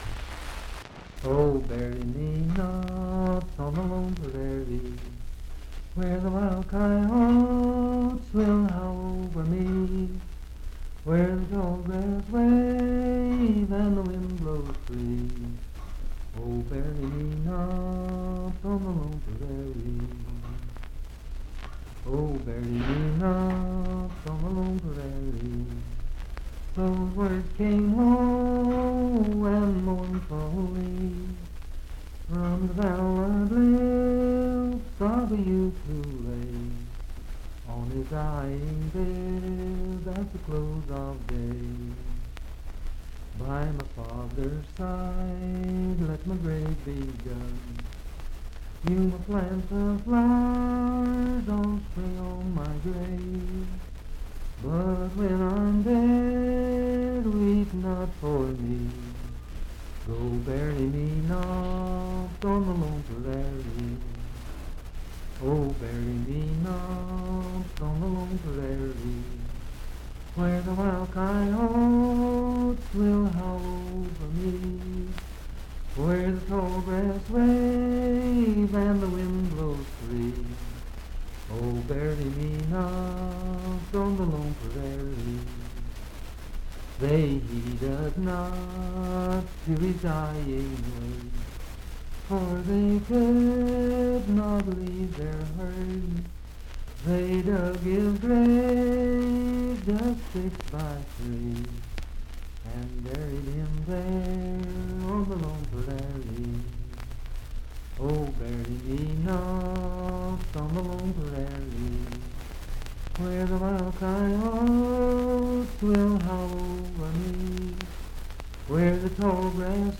Unaccompanied vocal music
Voice (sung)
Marlinton (W. Va.), Pocahontas County (W. Va.)